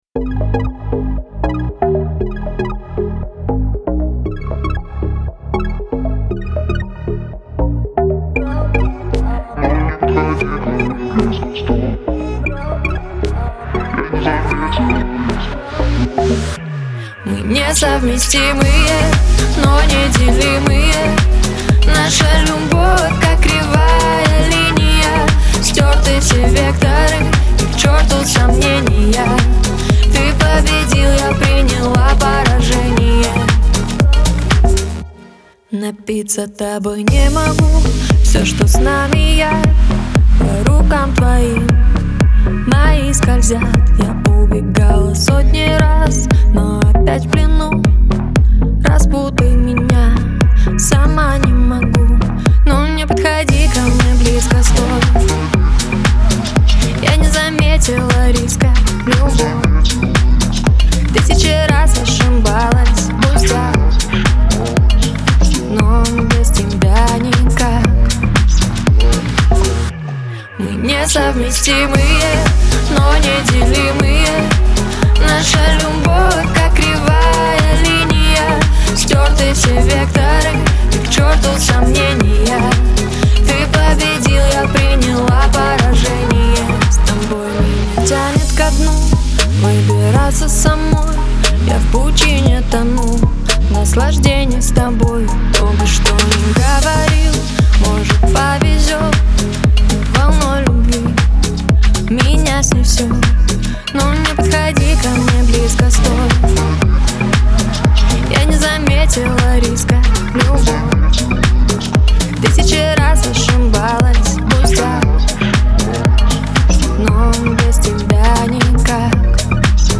это эмоциональный поп-трек с элементами электроники